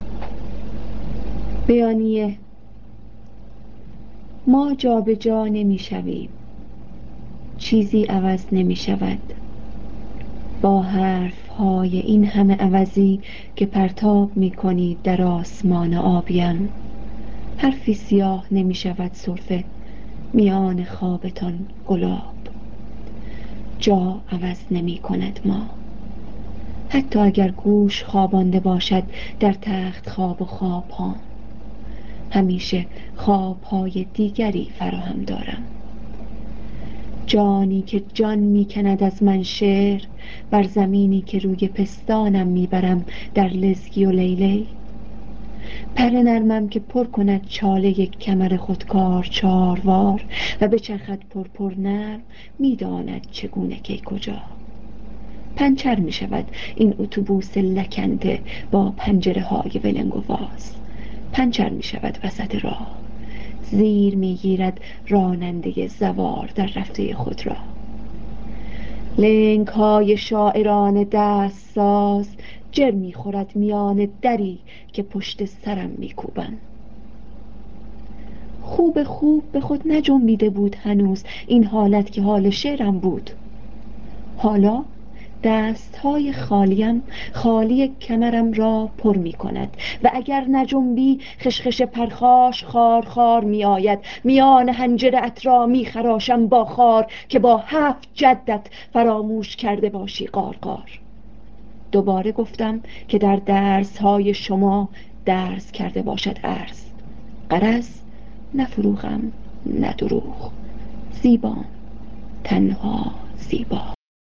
شعر با صدای شاعر